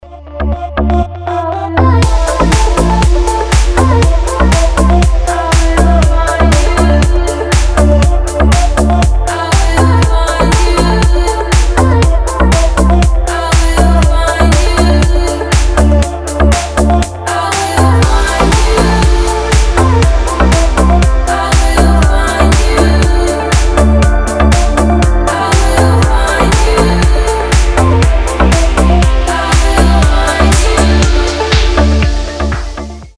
• Качество: 320, Stereo
женский вокал
deep house
Красивый Deep House с женским вокалом.